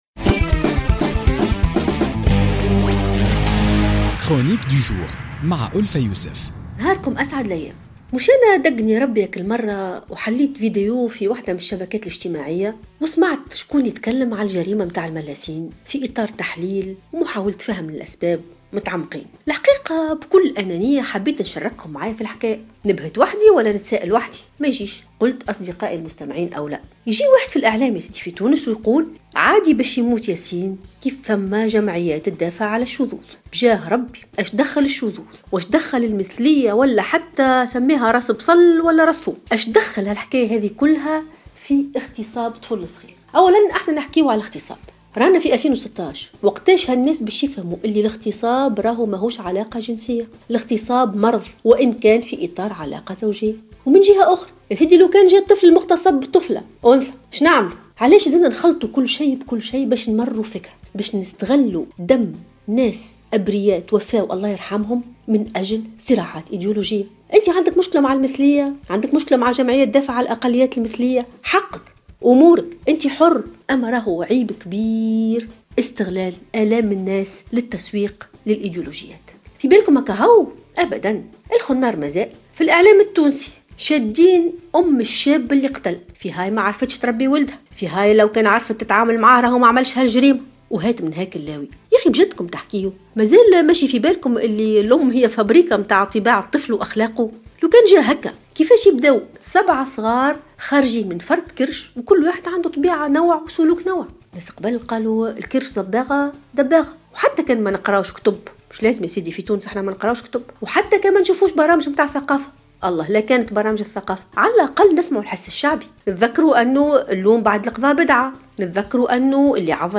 تحدثت الباحثة ألفة يوسف في افتتاحية اليوم الاثنين 23 ماي 2016 عن حادثة القتل البشعة